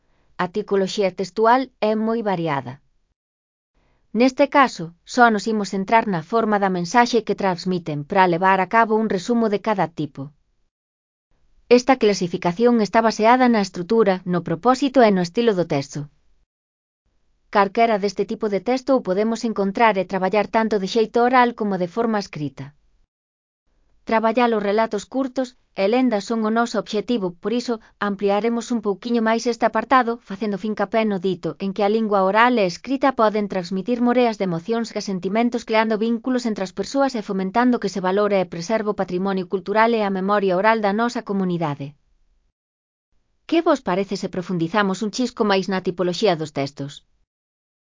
Elaboración propia (Proxecto cREAgal) con apoio de IA, voz sintética xerada co modelo Celtia.. Textos atendendo a forma da súa mensaxe. (CC BY-NC-SA)